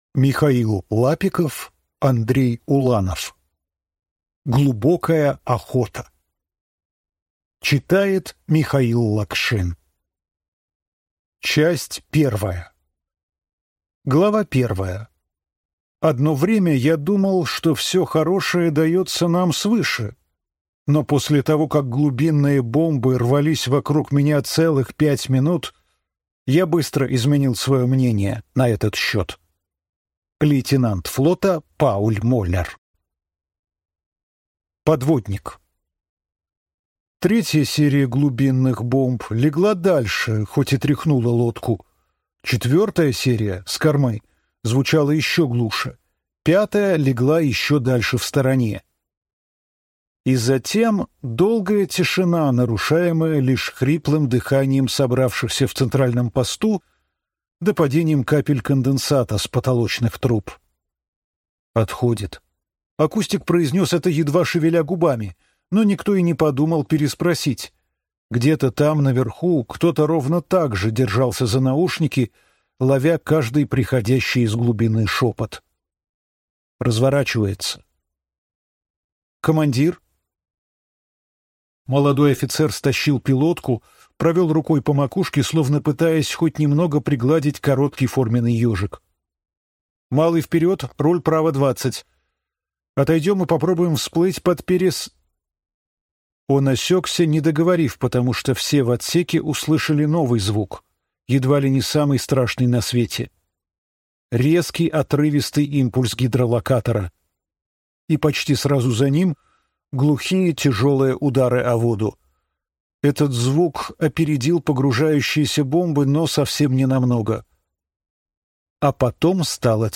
Аудиокнига Глубокая охота | Библиотека аудиокниг